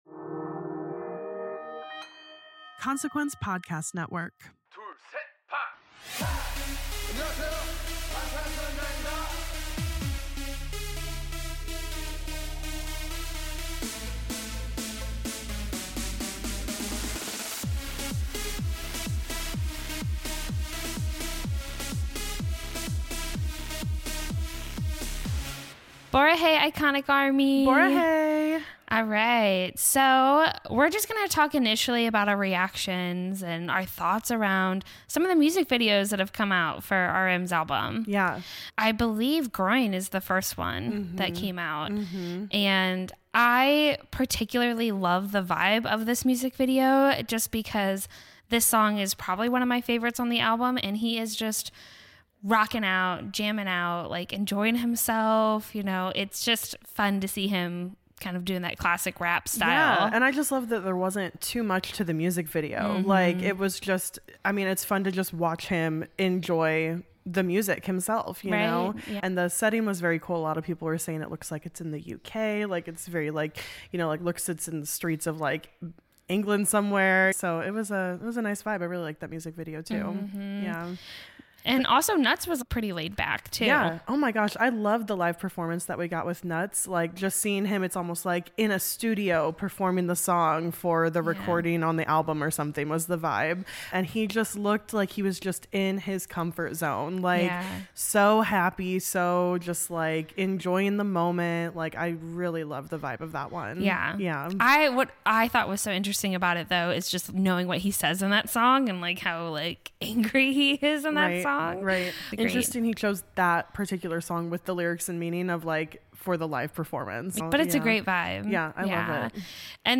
Listen to the two dive into the BTS member's new project.